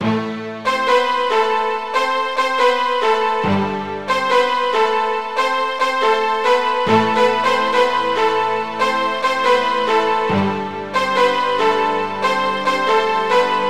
描述：弦乐、铜管和Timapani，使用DSK序曲
Tag: 140 bpm Trap Loops Strings Loops 2.31 MB wav Key : A